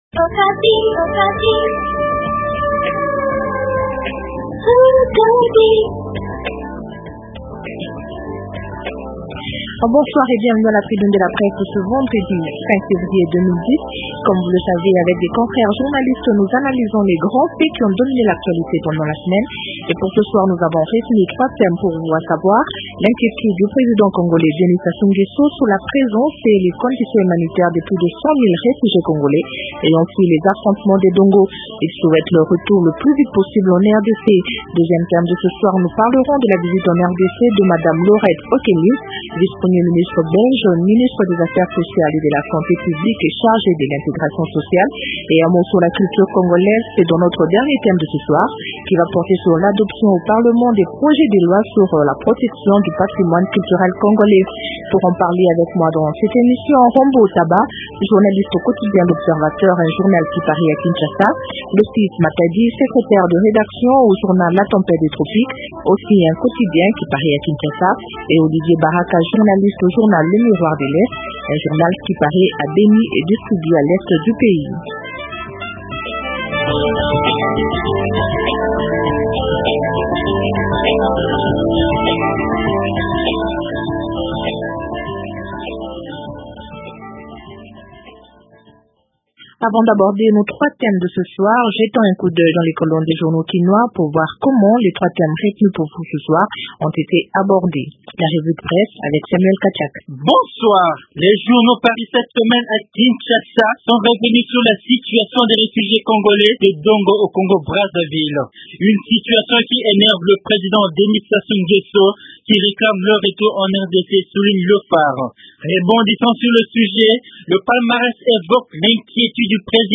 Trois thèmes au centre de la tribune de la presse de ce soir :